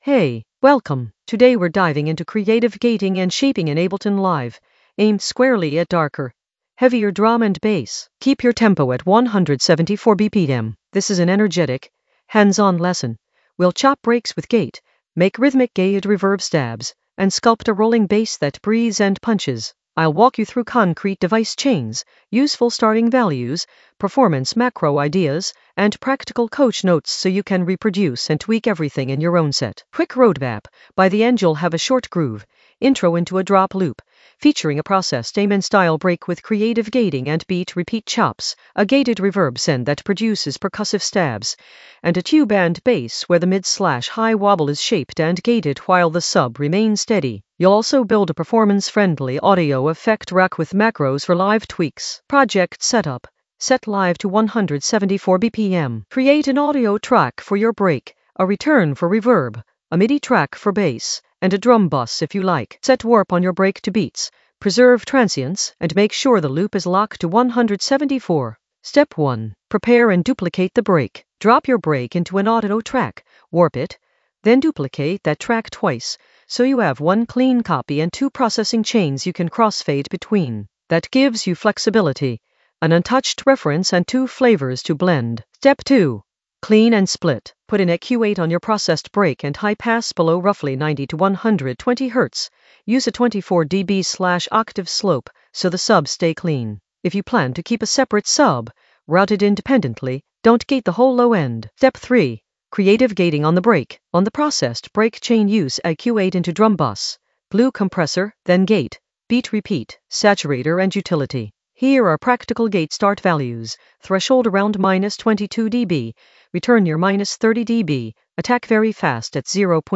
An AI-generated intermediate Ableton lesson focused on Using gate and shaper tools creatively in the FX area of drum and bass production.
Narrated lesson audio
The voice track includes the tutorial plus extra teacher commentary.
Teacher tone: energetic, clear, professional.